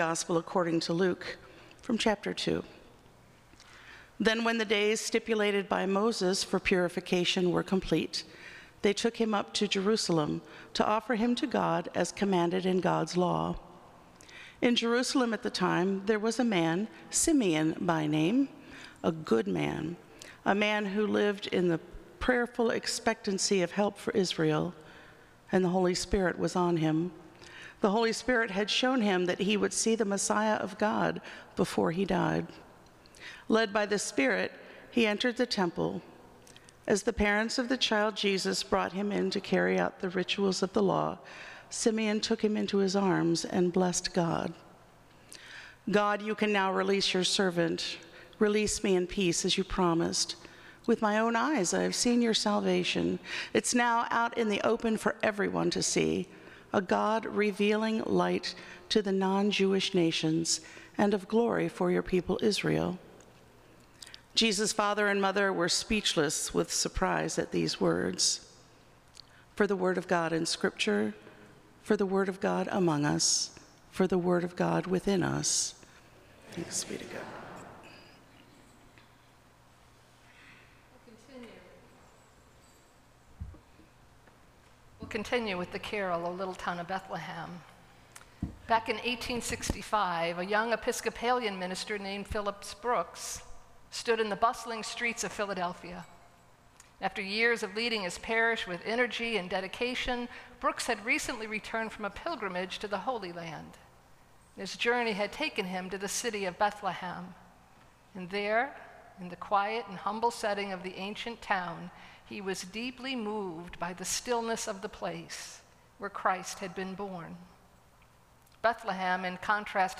AFUMC_sermon_12-29.m4a